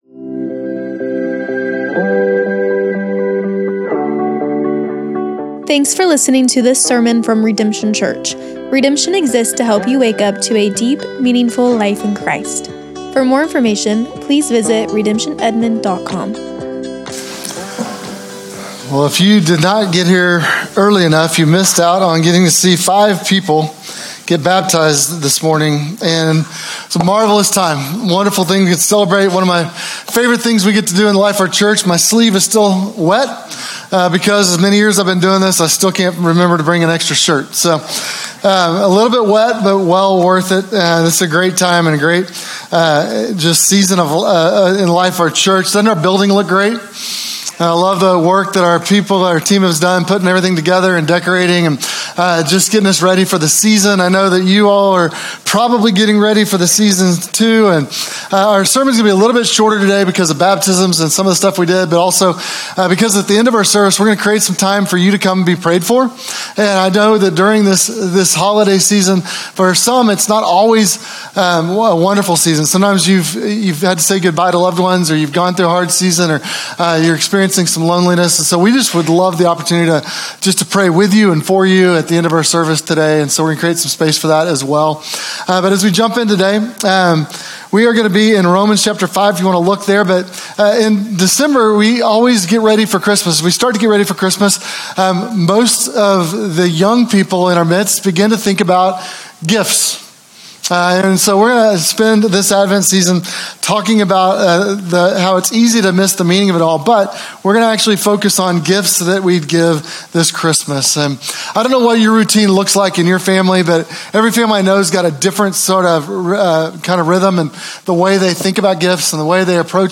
These are sermons that stand alone rather than being part of a specific sermon series.